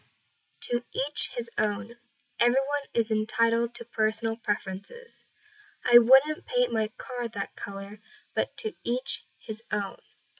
（誰かの車を見て） 英語ネイティブによる発音は下記のリンクをクリックしてください。